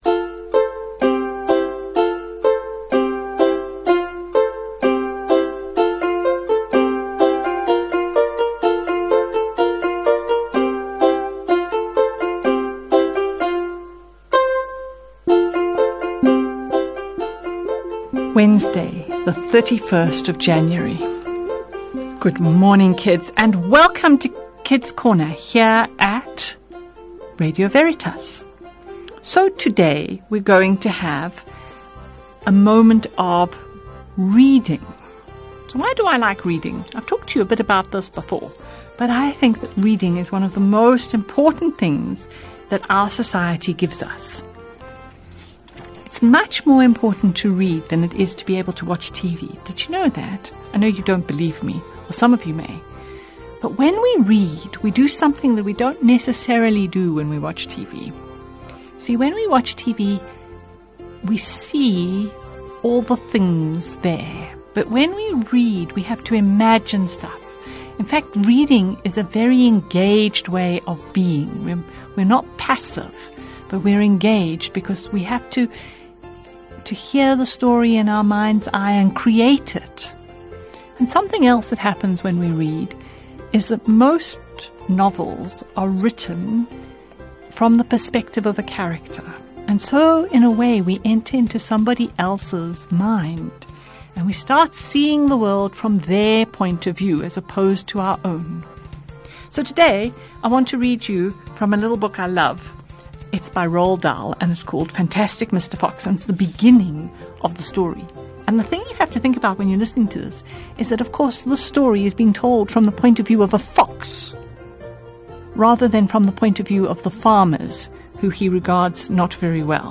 31 Jan Kids’ Corner – Reading: Fantastic Mr Fox.